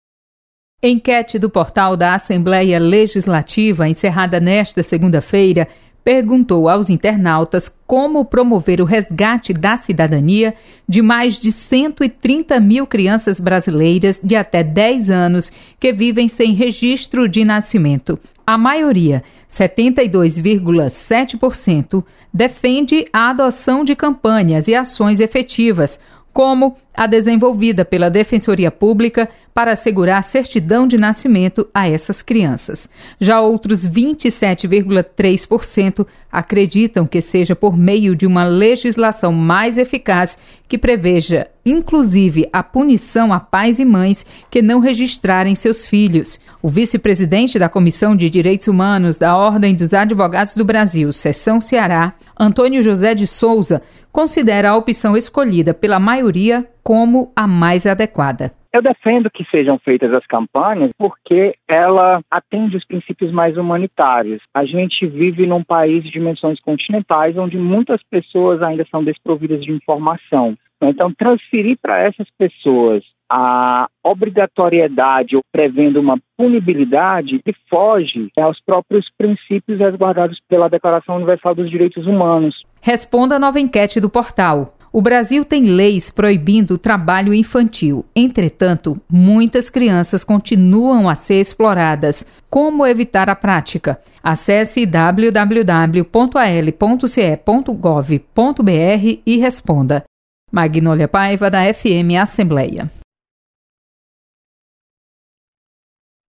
Enquete - QR Code Friendly